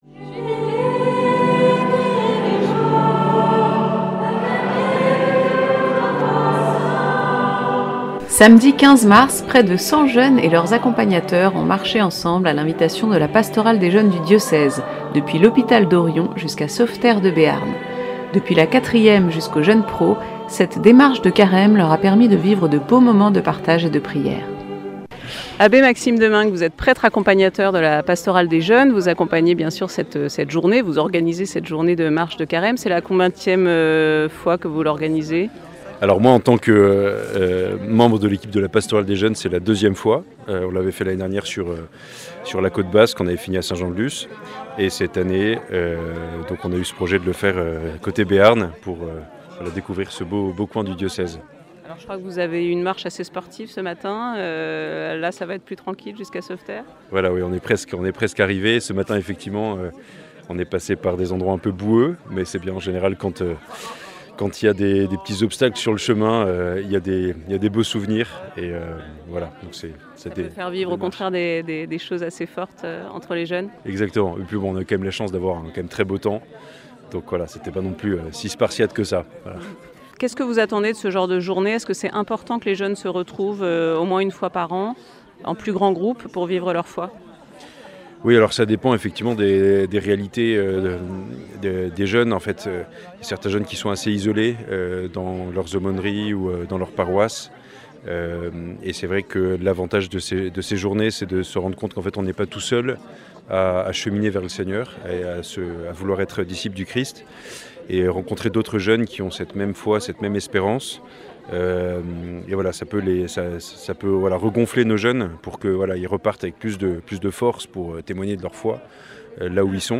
Reportage sur la marche des jeunes du 15 mars 2025.